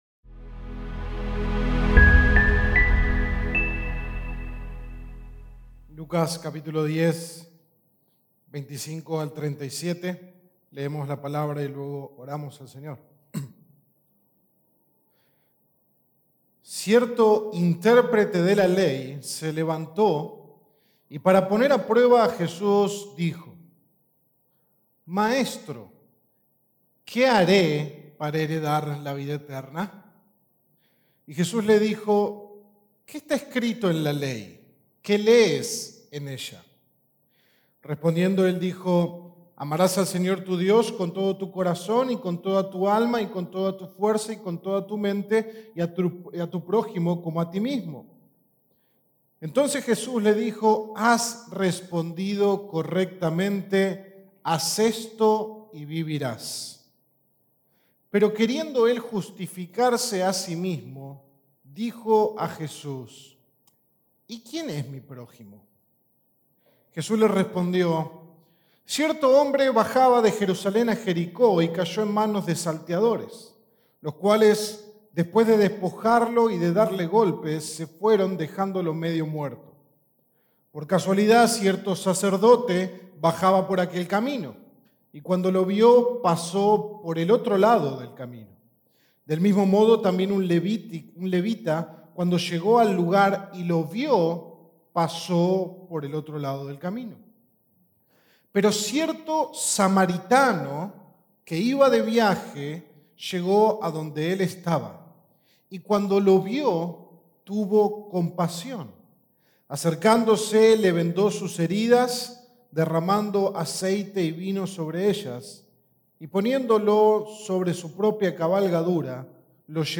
Sermón 44 de 45 en Lucas
Amas-como-alguien-que-es-amado-sermon-16-de-noviembre-WORDPRESS.mp3